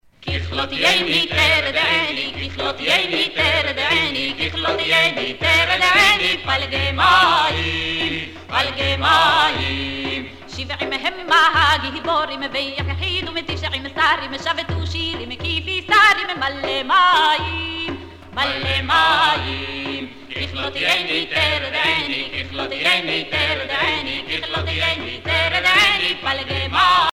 Chants et danses des pionniers
Pièce musicale éditée